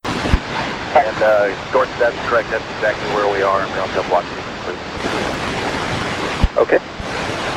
Audio recording: ascending first orbit (over Italy):